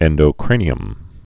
(ĕndō-krānē-əm)